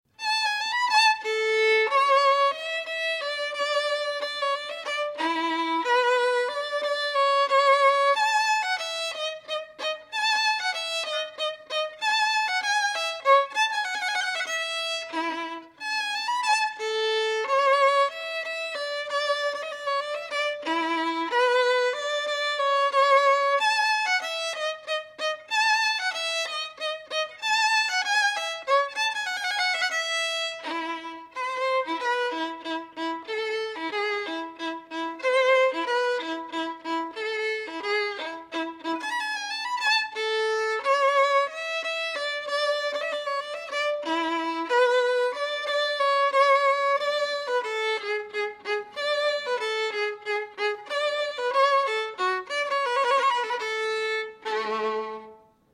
violin.wav